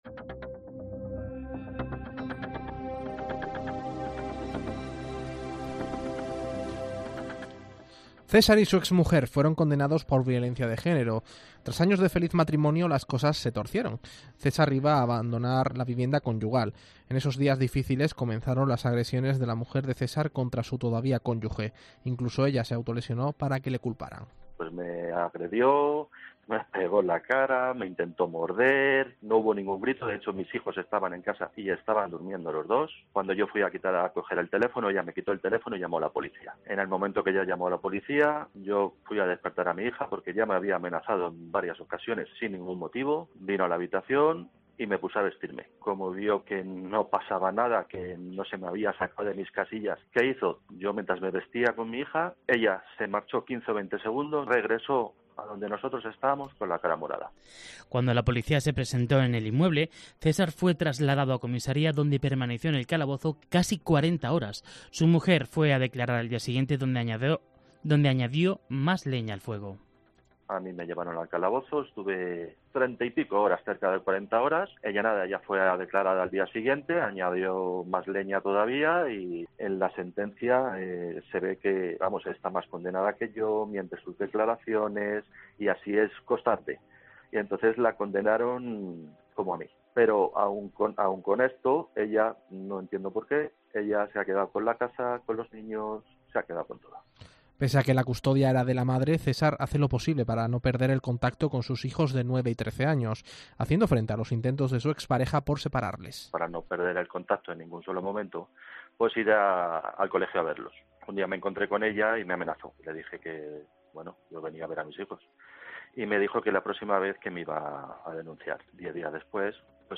El testimonio de un hombre maltratado por su pareja: “Mi exmujer está tratando de alejarme de mis hijos”